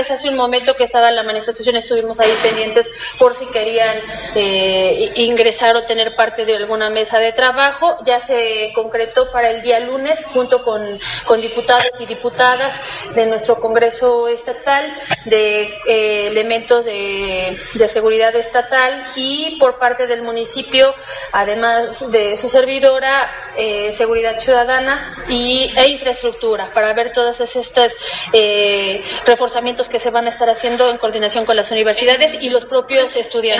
En entrevista, Rivera Vivanco abundó que debido a las demandas que se han efectuado en las calles, desde el martes de manera masiva por parte de las y los estudiantes de diversas universidades de Puebla; esto permite “visibilizar la urgencia que tenemos que hacer e involúcranos todos como sociedad” y donde los tres órdenes de gobierno municipal, estatal y federal deben reforzar de manera coordinada sus estrategias de seguridad con las y los ciudadanos, al tiempo de comprometerse a redoblar “esfuerzos”.